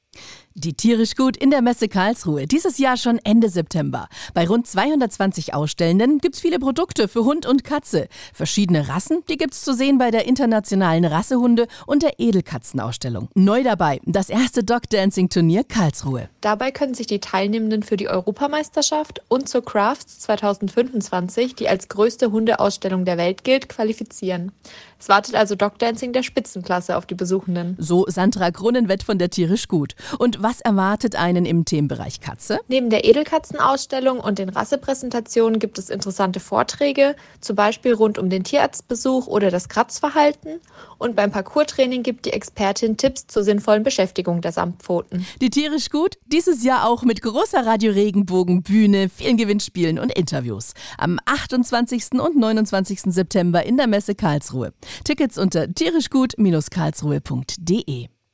infomercial-tierisch-gut.mp3